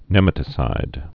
(nĕmə-tĭ-sīd, nə-mătĭ-)